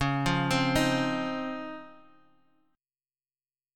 DbM9 chord